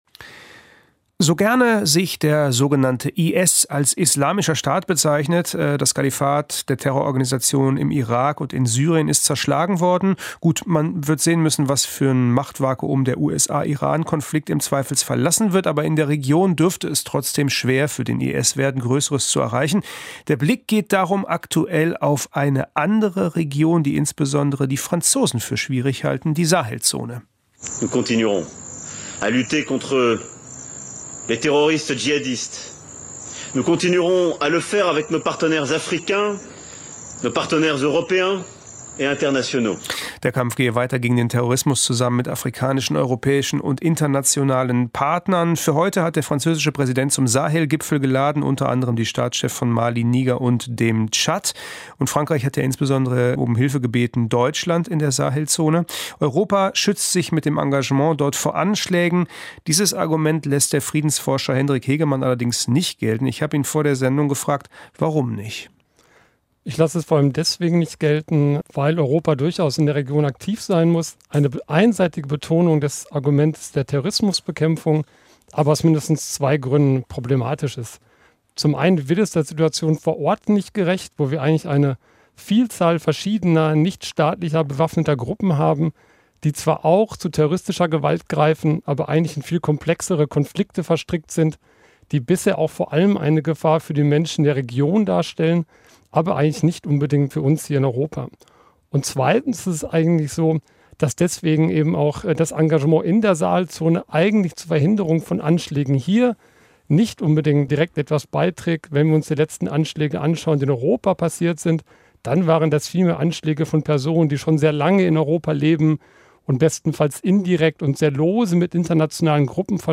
Anti-Terrorkampf in der Sahelzone - Interview mit dem WDR-Hörfunk
Das ausführliche Interview aus der Sendung WDR 5 Politikum können Sie hier nachhören.